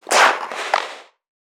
NPC_Creatures_Vocalisations_Infected [34].wav